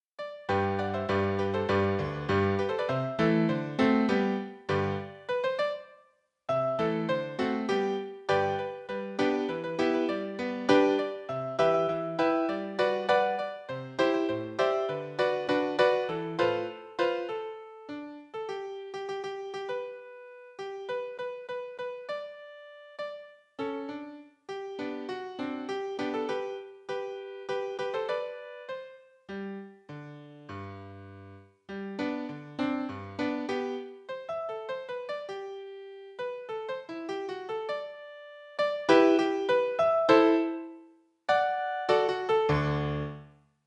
En attendant, pour connaître la mélodie (car vous imaginez bien que j’ai oublié celle que ma grand-mère a utilisée en 1978 pour me chanter une partie de la chanson), j’ai utilisé un logiciel qui scanne les partitions musicales et les transforme en fichiers audio.
Cette fois, ce n’est pas du piano mais de la flûte.